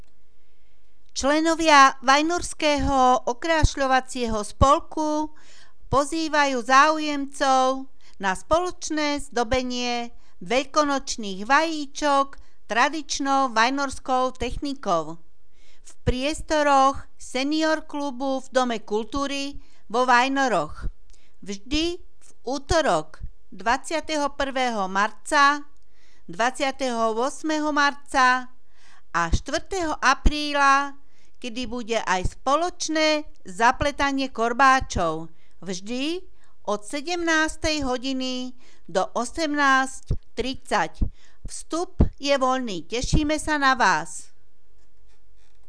Hlásenia miestneho rozhlasu 20.3. (Zdobenie veľkonočných vajíčok s VOS, pozvánka)